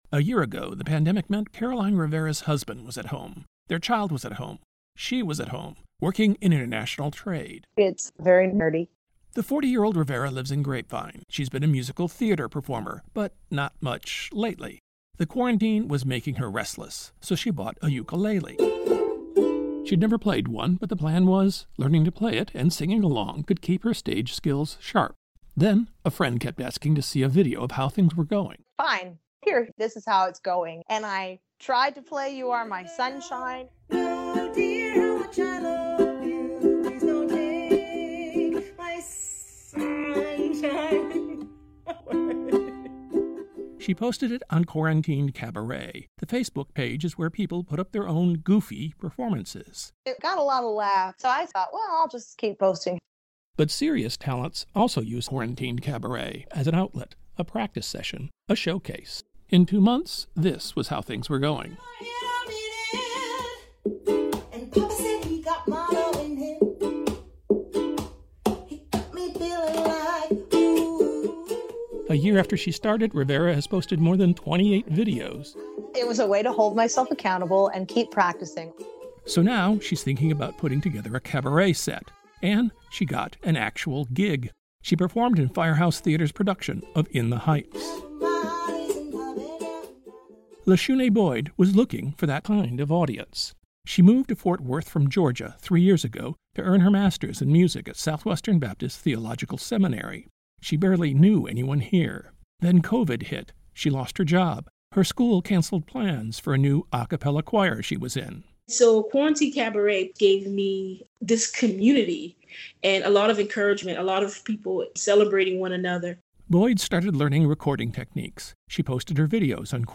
Special report: Here’s why 45,000 people are fans of North Texas’ Quarantined Cabaret